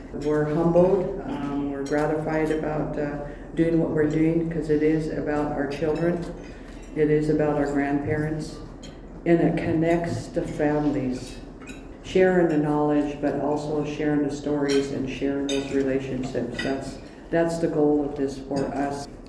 On Wednesday, Elwha Tribal Chairwoman Frances Charles described for the members of the Port Angeles Chamber of Commerce the importance of hosting the international event for the first time in 20 years.